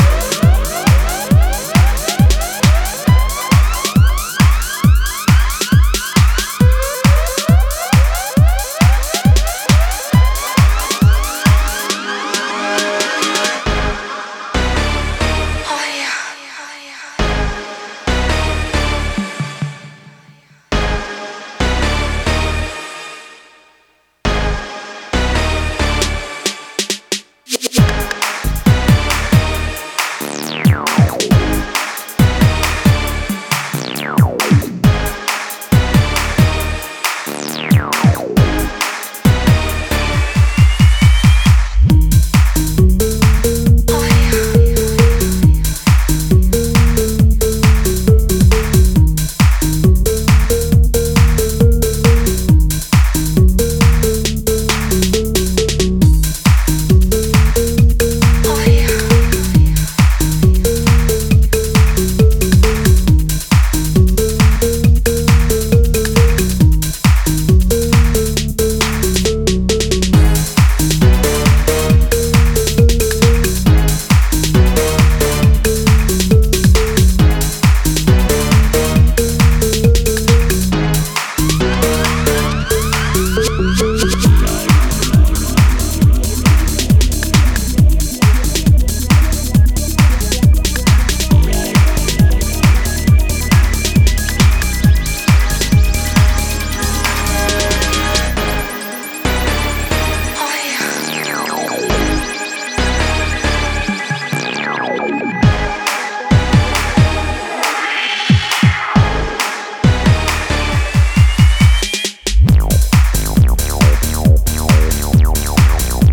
offering a rich blend of electronic sounds